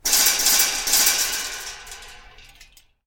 关于苏打水气体晃动音效的PPT模板_风云办公